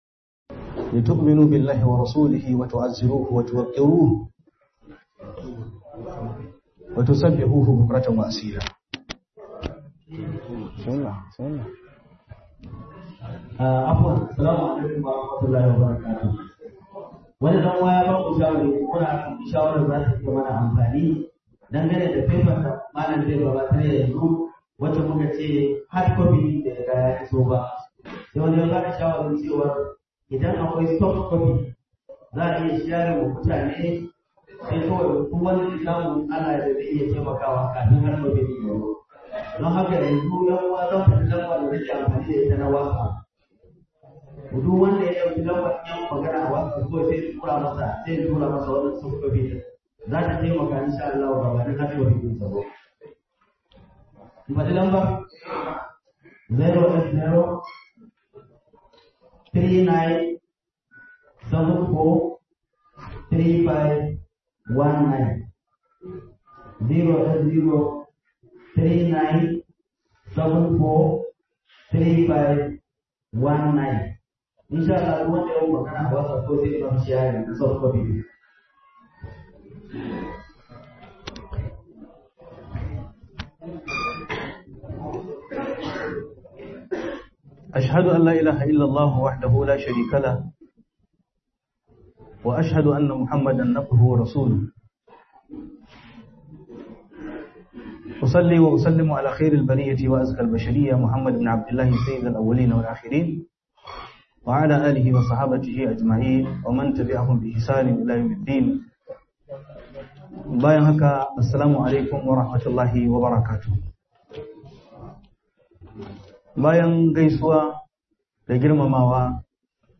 Hakkokin Manzon Allah saw Tsakanin girmamawa da tauyewa - Muhadara